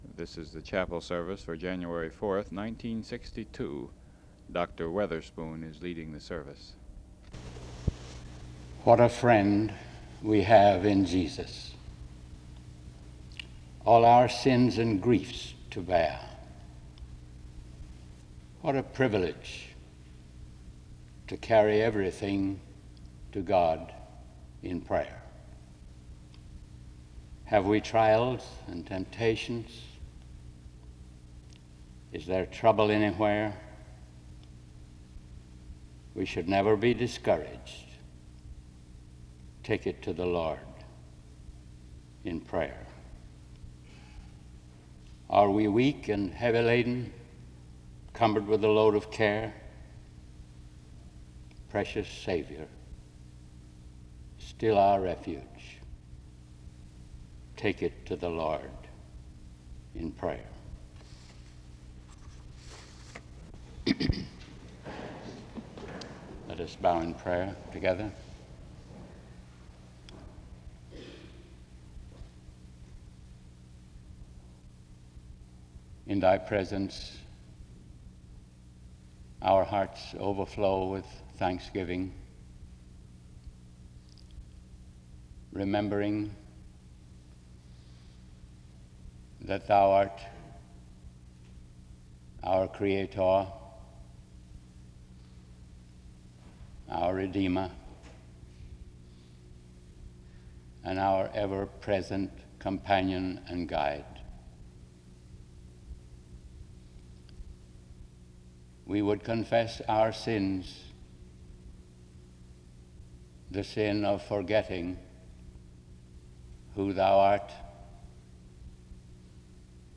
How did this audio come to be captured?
Location Wake Forest (N.C.)